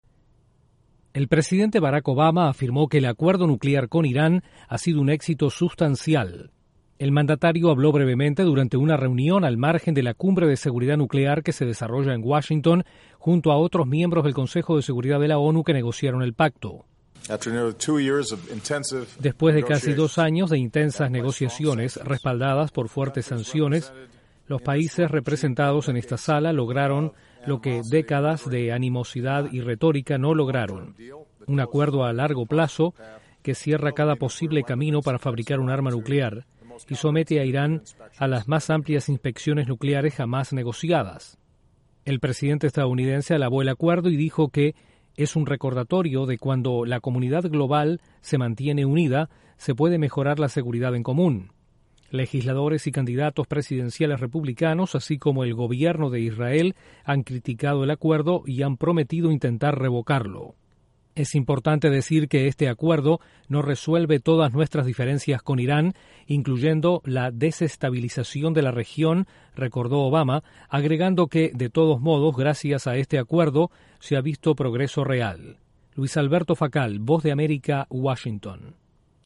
El presidente de EE.UU., Barack Obama, califica de un "éxito sustancial" el acuerdo nuclear con Irán. Desde la Voz de América en Washington informa